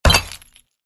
На этой странице собраны разнообразные звуки, связанные с кирпичами: от стука при строительстве до грохота падения.
Удар о кирпичную стену